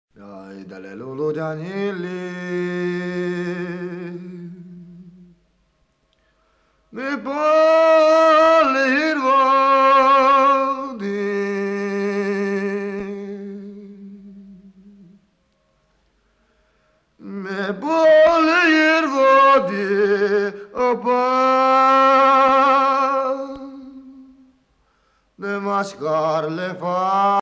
Cigany Hallgato és tancritmus
Pièce musicale éditée